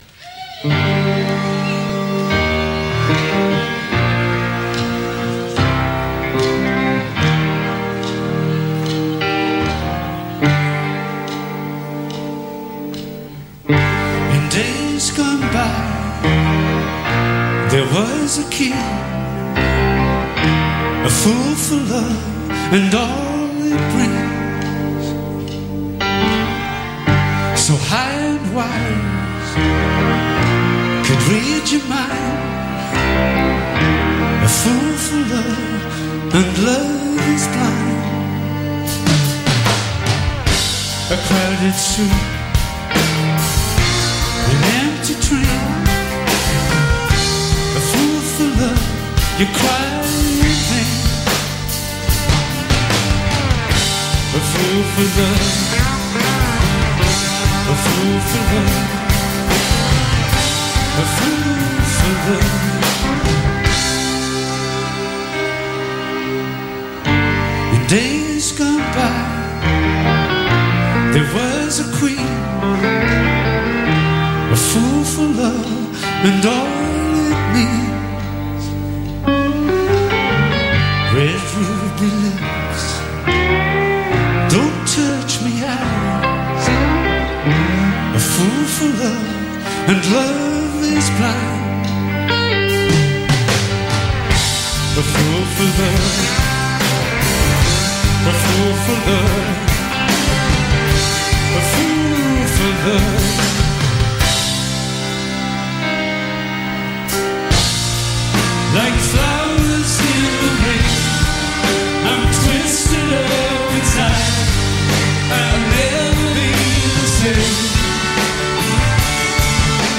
“elegant, seductive croon”